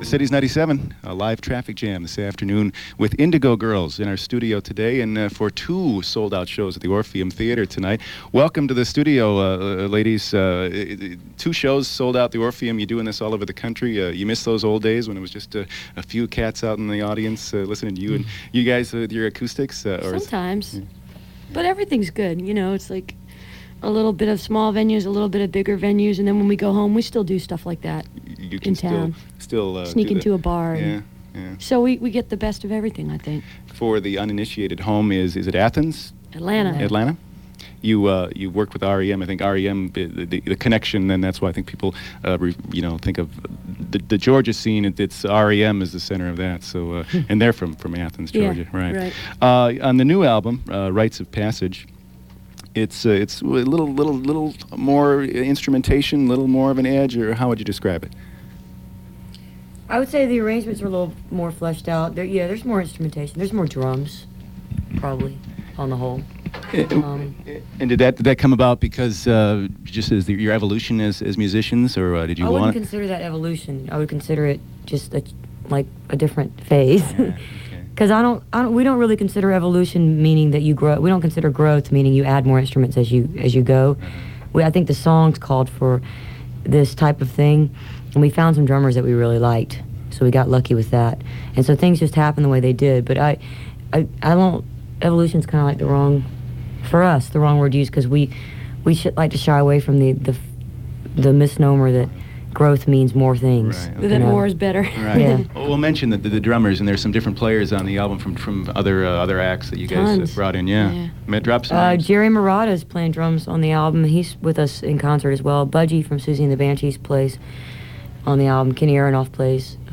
03. interview (2:28)